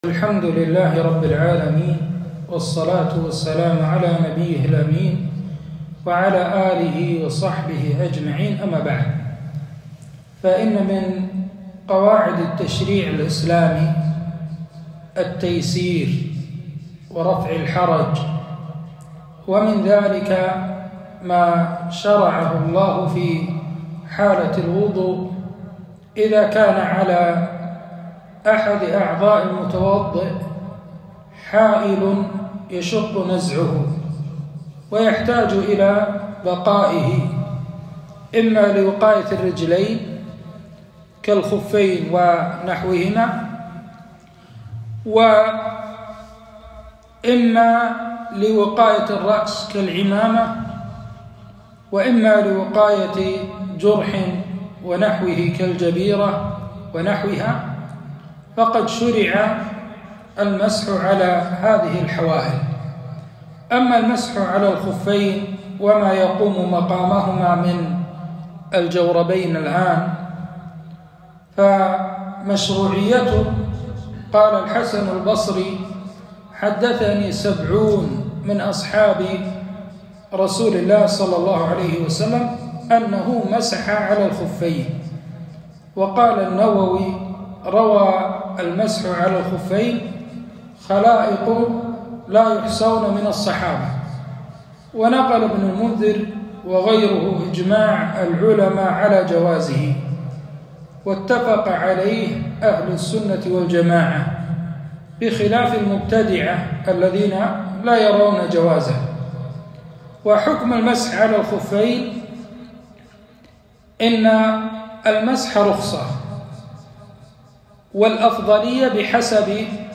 كلمة - أحكام مختصرة في المسح على الخفين والجوربين والعمامة